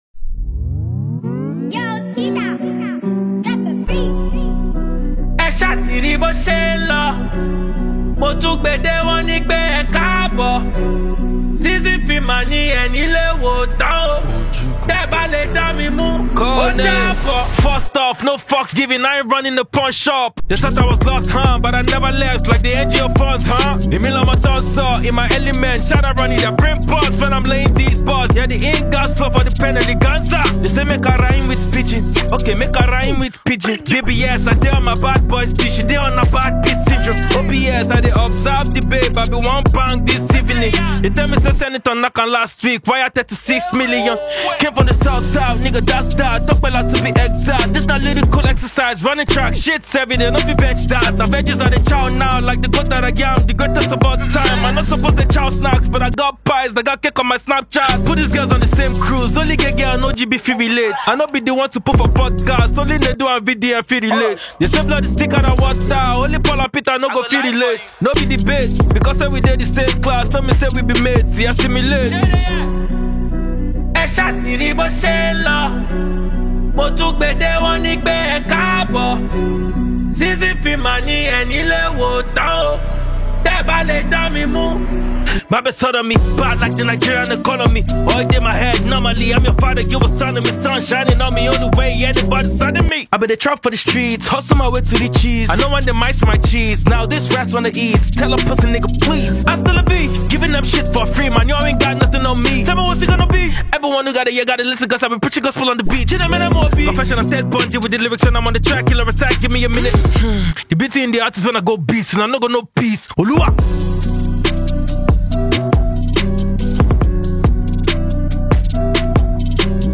Nigerian talented rapper and songwriter
hard rap tune